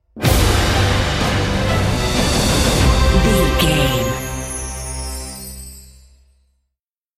Uplifting
Ionian/Major
energetic
brass
drums
piano
strings